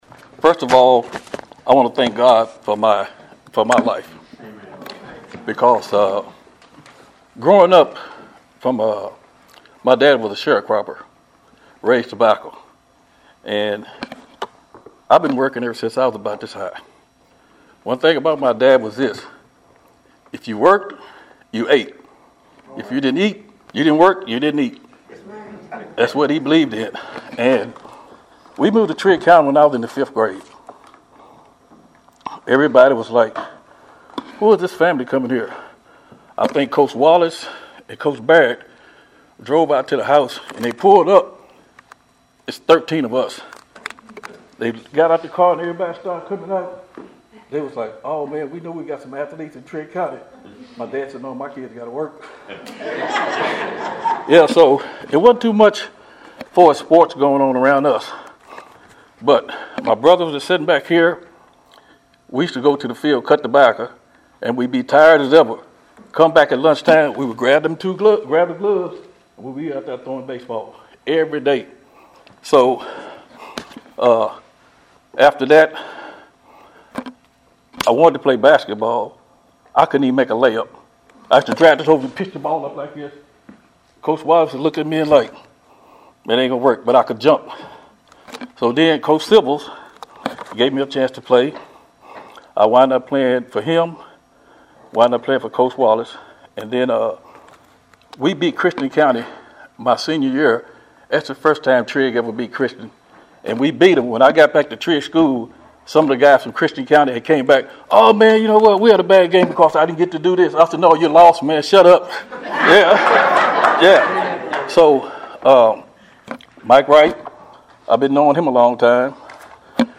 acceptance speech